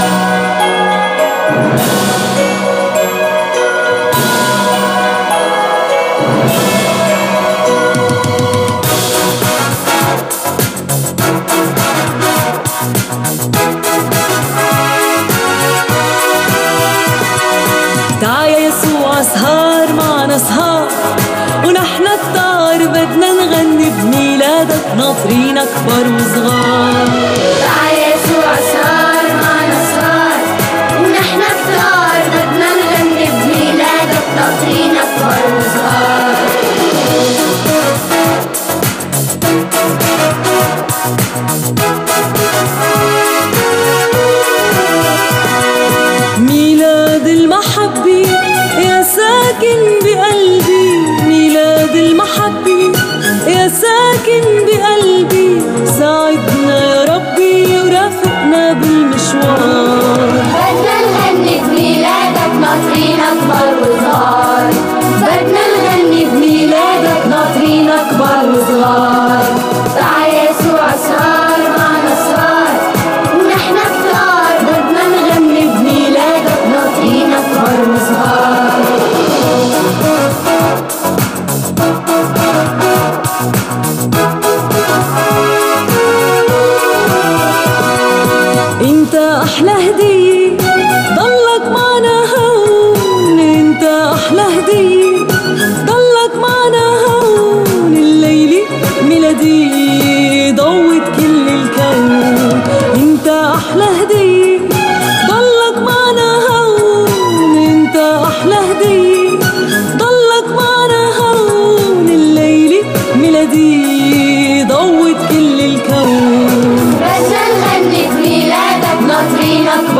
اغنية ميلادية جديدة
بحرفية عالية واحساس كبير